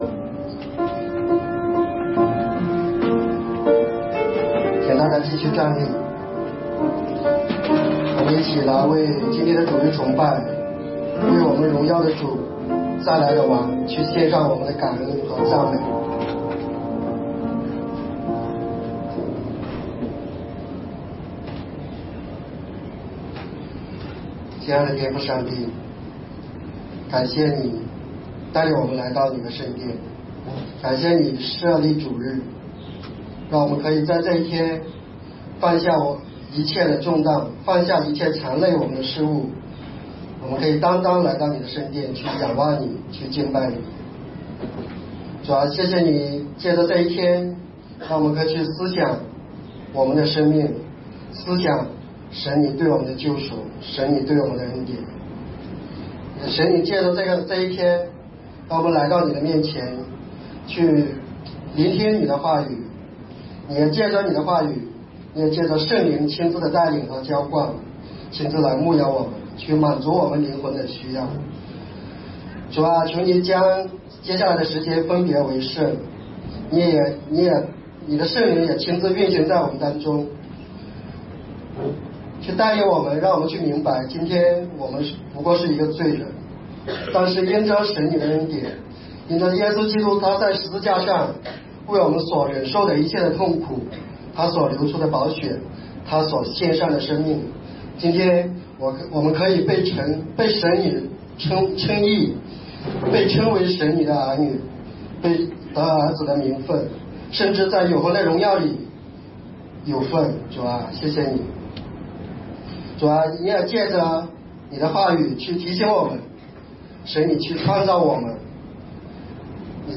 马太福音第45讲 弥赛亚的受难与复活II 2018年4月22日 上午10:36 作者：admin 分类： 马太福音圣经讲道 阅读(6.2K